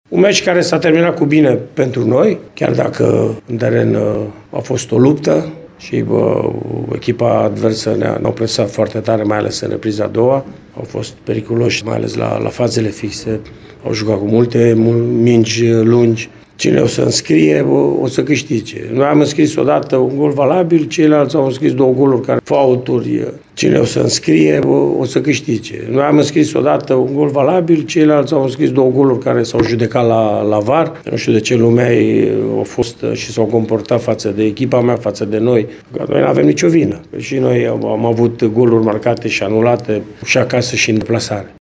Antrenorul Mircea Rednic a vorbit și despre terenul greu și ninsoarea căzută în bună parte din prima repriză: